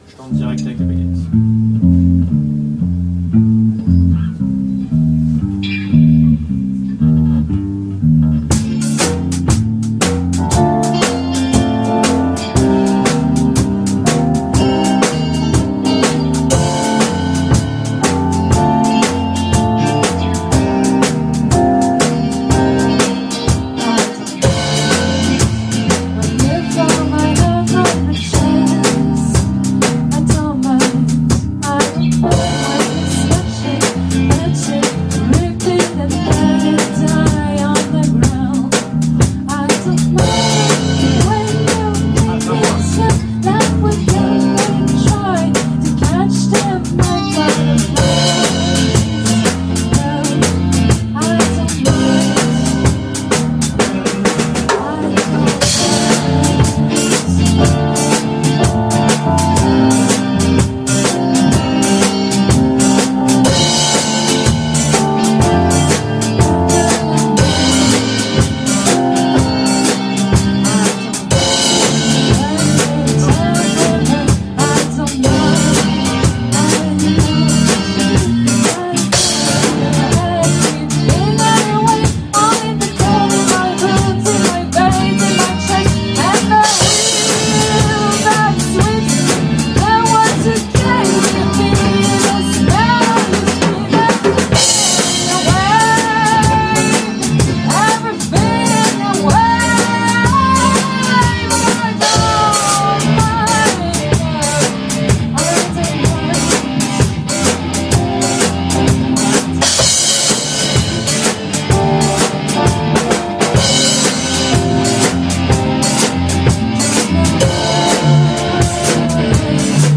Impro
Walking Bass